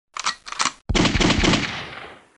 spe_shooting.mp3